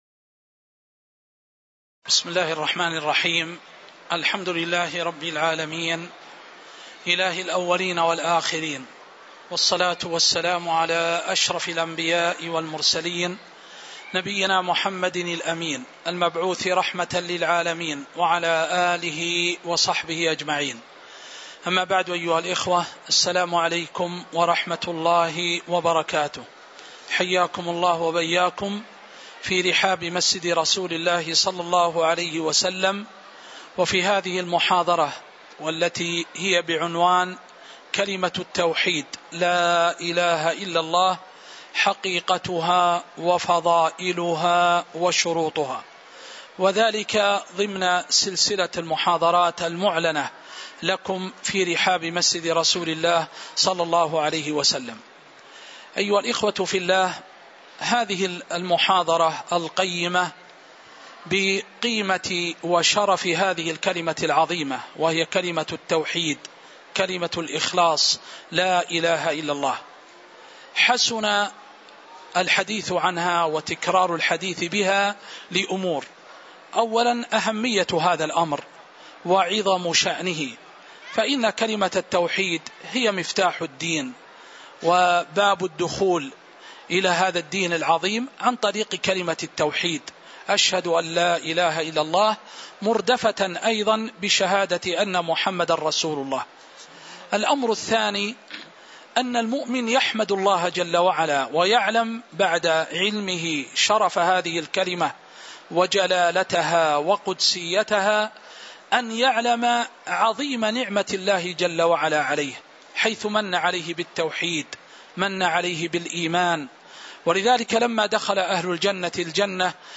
تاريخ النشر ٢٣ ربيع الأول ١٤٤٥ هـ المكان: المسجد النبوي الشيخ